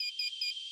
SOSloop.wav